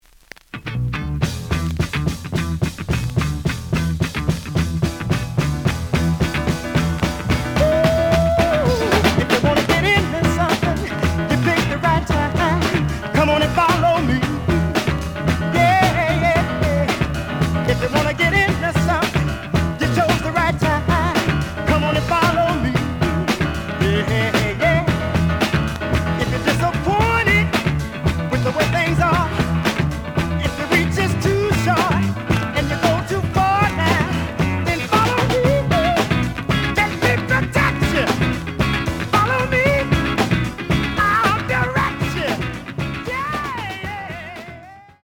The audio sample is recorded from the actual item.
●Genre: Soul, 70's Soul
Some click noise on A side due to scratches.